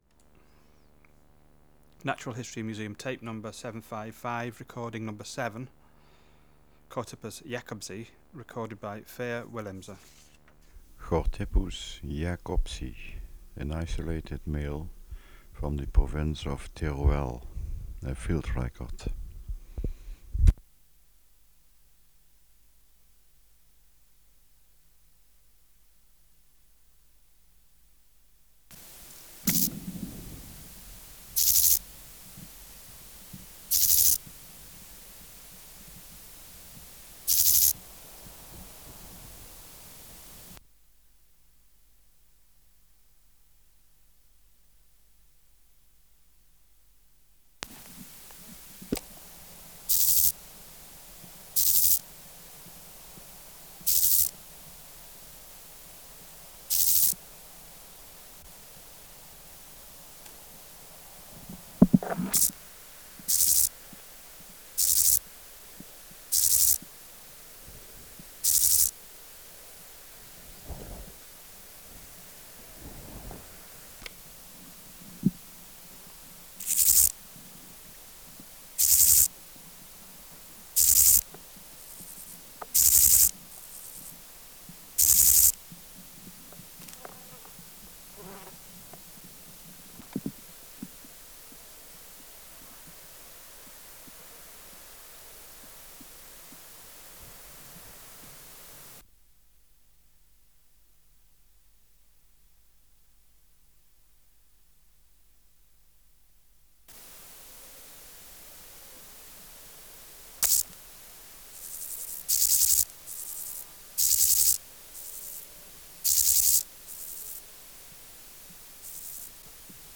Natural History Museum Sound Archive Species: Chorthippus (Glyptobothrus) jacobsi
Biotic Factors / Experimental Conditions: Isolated male
Microphone & Power Supply: AKG D202 E (LF circuit off) Distance from Subject (cm): 15 Windshield: On base and top